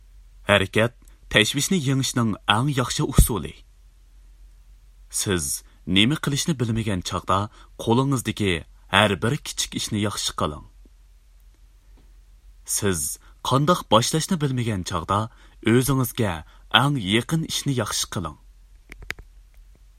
实惠男声-广告